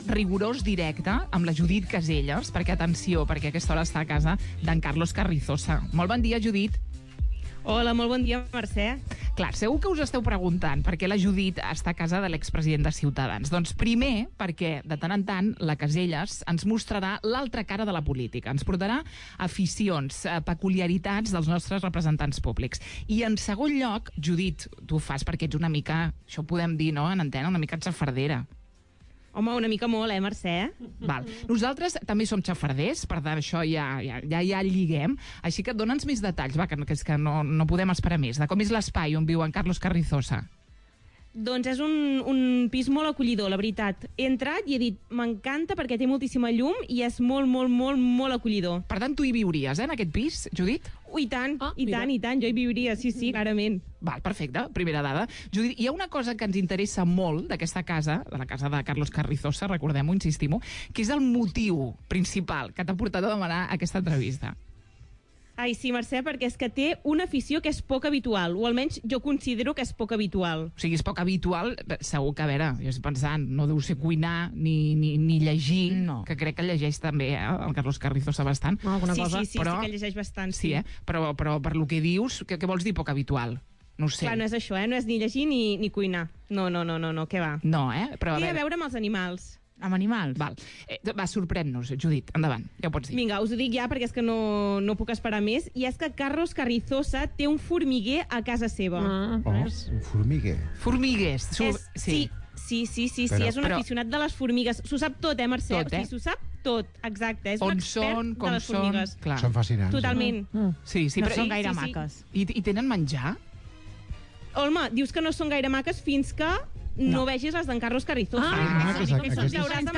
Escolta l'entrevista a l'expresident de Ciutadans Carlos Carrizosa
En exclusiva a El Matí a Ràdio Estel, les ha presentat i ha ensenyat els seus habitacles.